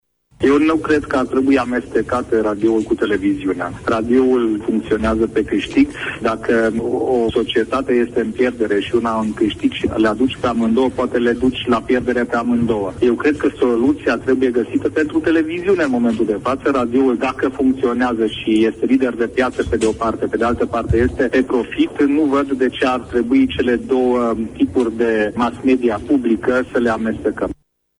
În timp ce Societatea Română de Radiodifuziune are profit, Televiziunea înregistrează datorii foarte mari, care necesită o rezolvare punctuală. Aprecierile aparţin deputatului Szabó Ödön – vicelider al grupului UDMR din Camera Deputatilor – şi au fost făcute astăzi la Radio România Actuaţităţi.